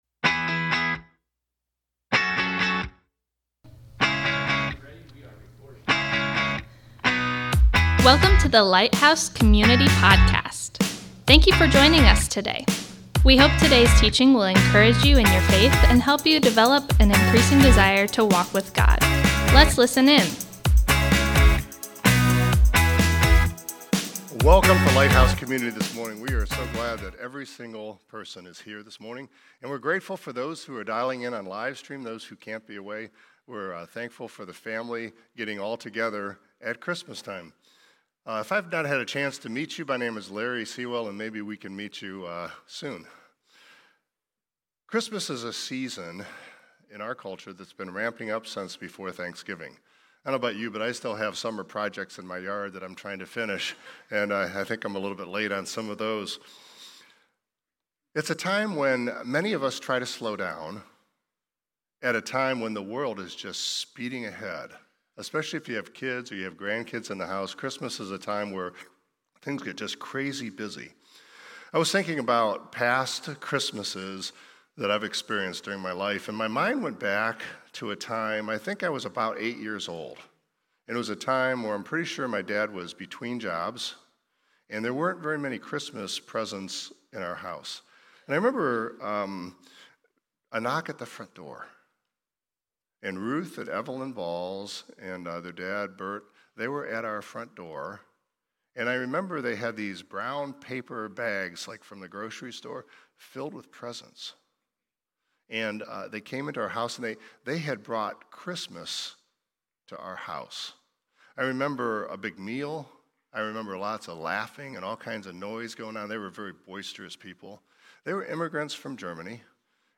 Thank you for joining us today as we come together to worship!